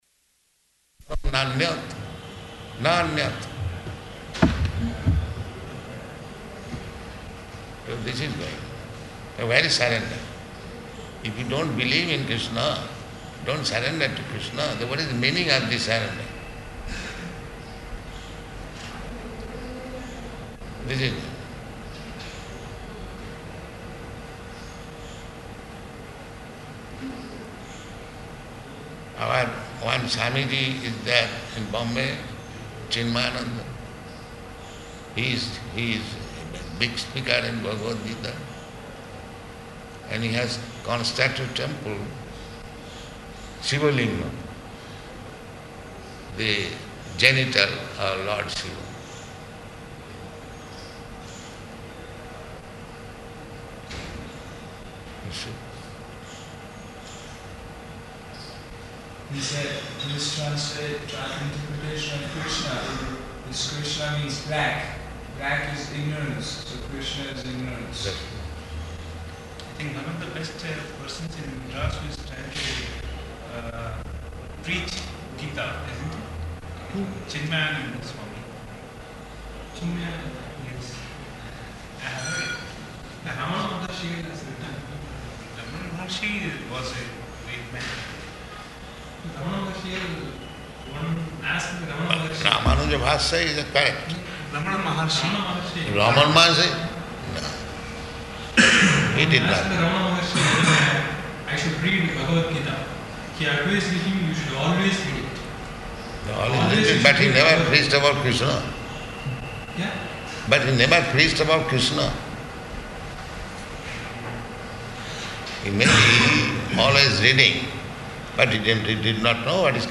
Conversation with Indian Guests
Conversation with Indian Guests --:-- --:-- Type: Conversation Dated: April 12th 1975 Location: Hyderabad Audio file: 750412R1.HYD.mp3 Prabhupāda: ...nānyat. Na anyat.